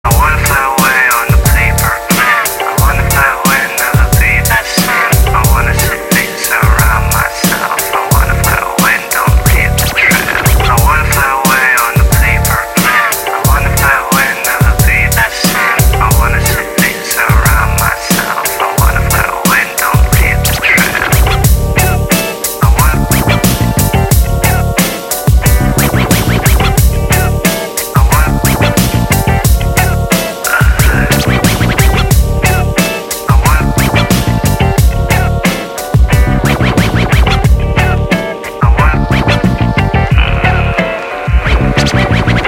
• Качество: 128, Stereo
Хип-хоп
Electronic
breakbeat